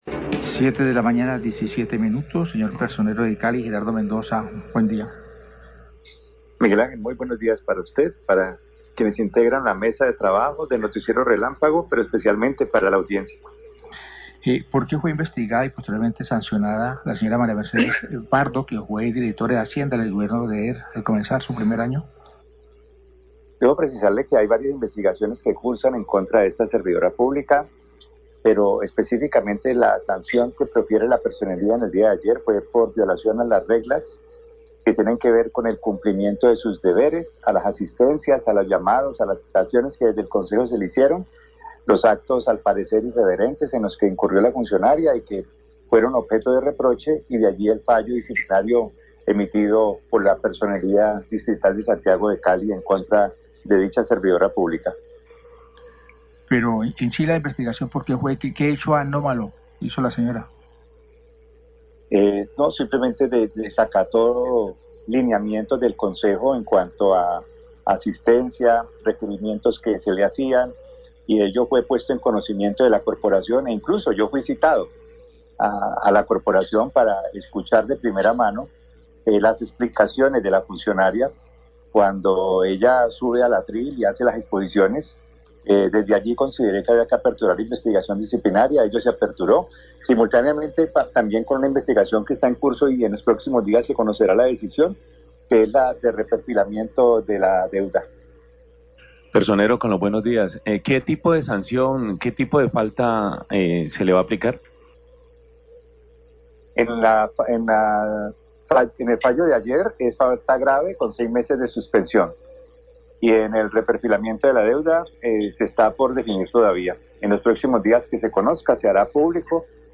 Radio
Personero de Cali habla sobre el fallo disciplinario contra María Mercedes Prado Daza, exdirectora del Departamento de Hacienda y, también sobre las investigaciones contra el secretario de Movilidad, Gustavo Orozco.